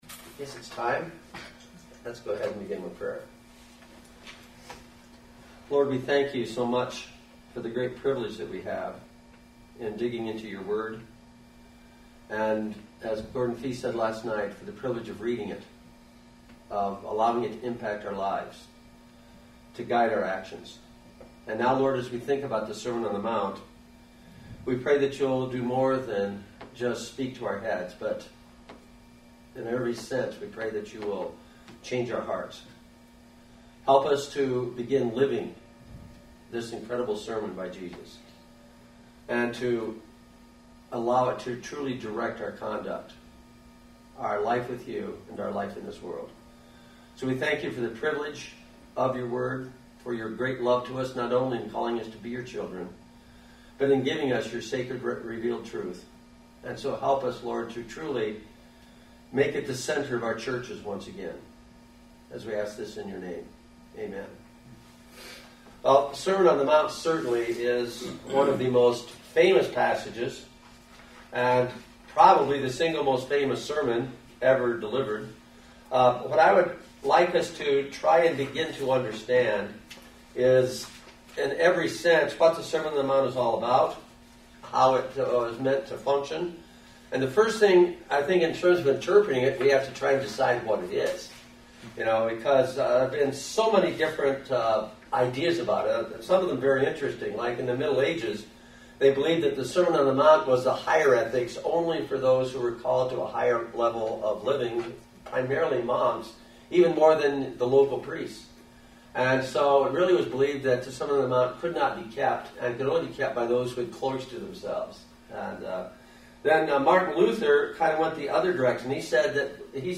For All It's Worth Conference: Breakout Session